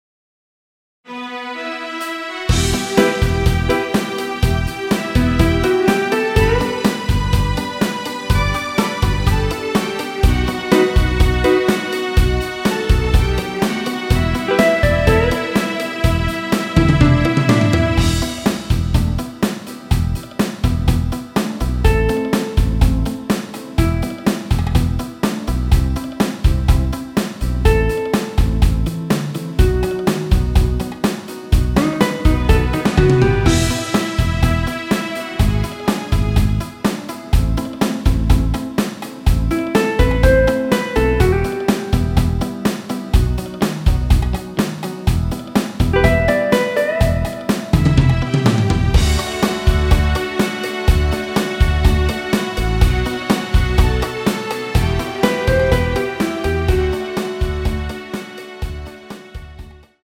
Em
앞부분30초, 뒷부분30초씩 편집해서 올려 드리고 있습니다.
중간에 음이 끈어지고 다시 나오는 이유는